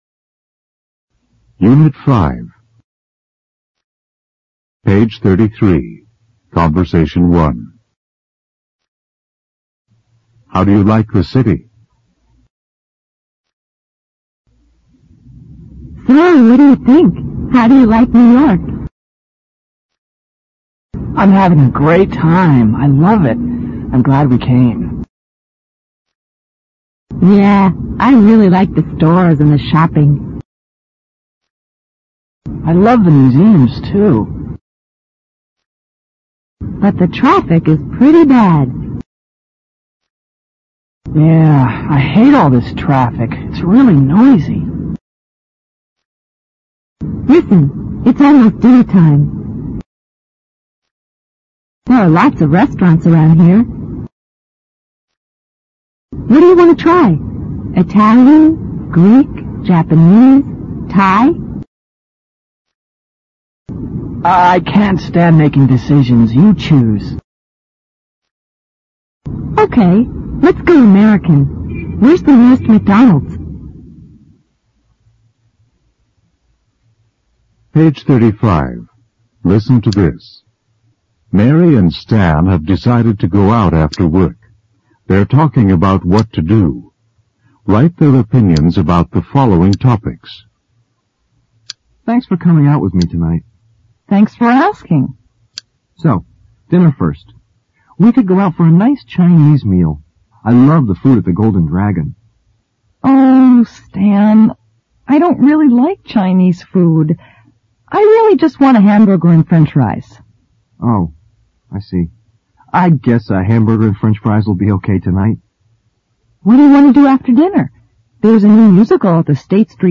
简单英语口语对话 unit5_conbersation1_new(mp3+lrc字幕)